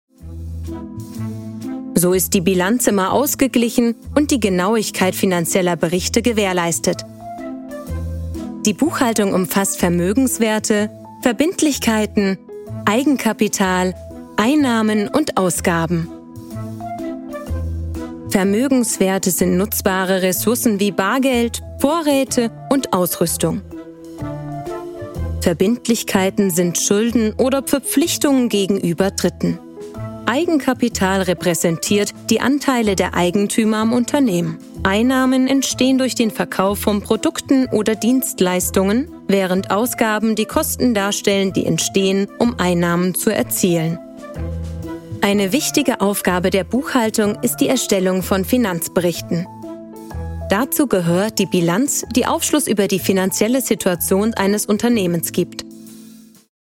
Hier ein paar Audio- und Video-Beispiele – von sinnlich, ernst oder sachlich über unbeschwert und heiter hin zu aufgebracht und verzweifelt.
Imagefilme, Produktvideos & Erklärfilme